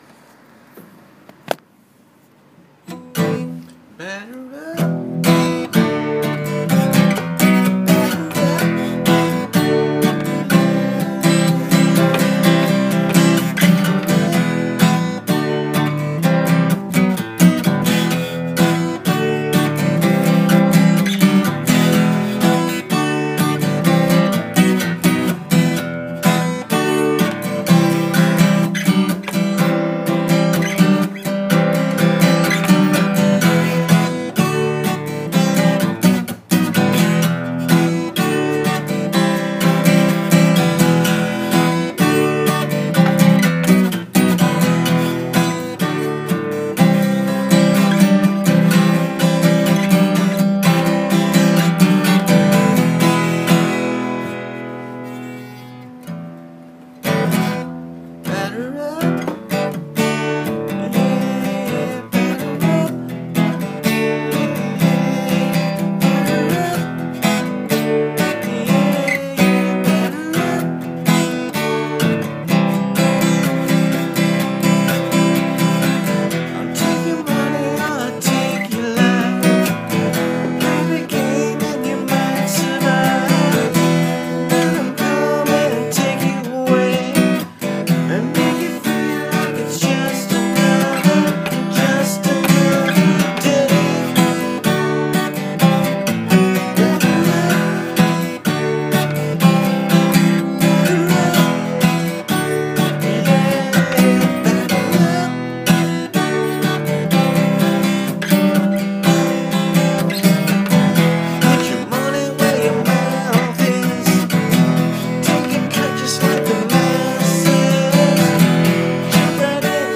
Although initially recorded on an old Tascam 2-track recorder, (or my phone), in my living room, with some catchy melodies and heartfelt lyrics, these demos encapsulate the essence of my style of rock music.
I wrote and recorded this in about an hour and mess up the words quite a bit. Another crappy phone recording in haste so I wouldn't forget it.